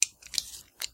工具 剃须刀 Out01
描述：正在处理盒式切割机的声音。 此文件已标准化，大部分背景噪音已删除。没有进行任何其他处理。
Tag: 切割机 缩回 刀片 刀具 延伸 剃须刀